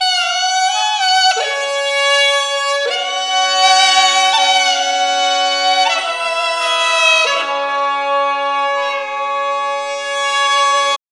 CHINHORNVL-L.wav